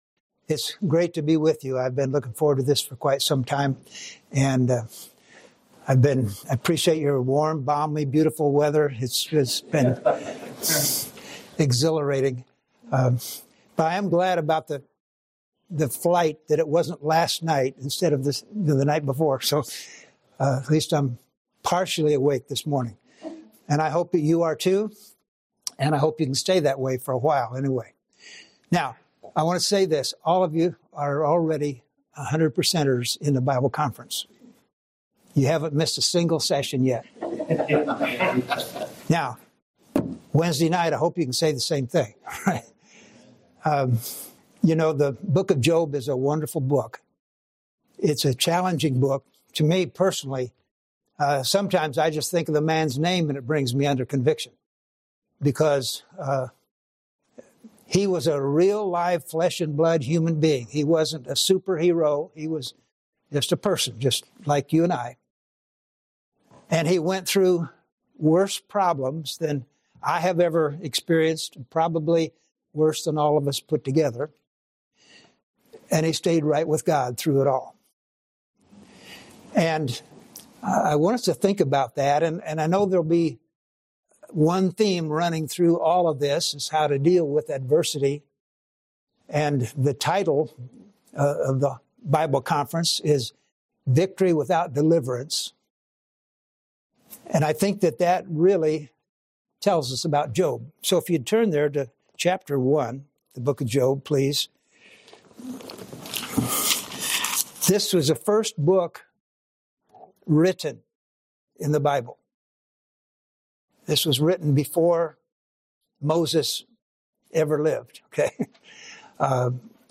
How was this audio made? Show Details → Sermon Information Title The Greatness of Job Description Message #1 of the 2025 Bible Conference. Chapter 1 reveals that Job's greatness was not just seen in his substance, but in his response to adversity.